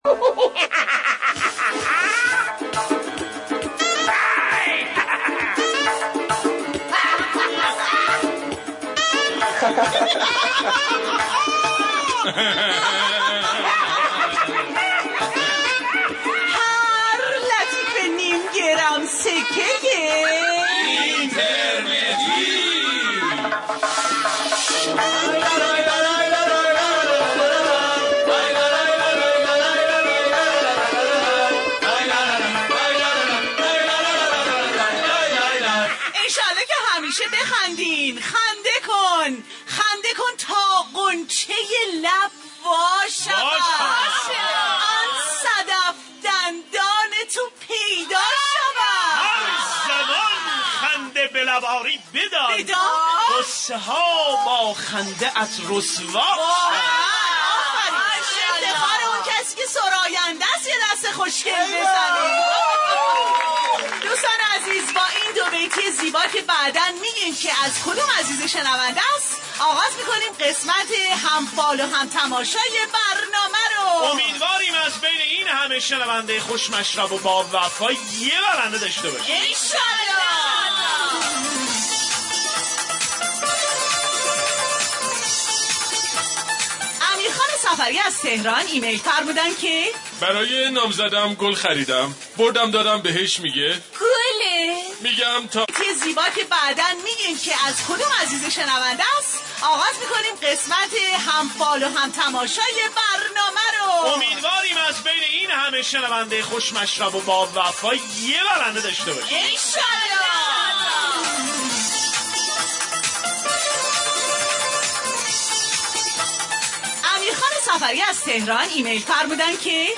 برنامه طنز جمعه ایرانی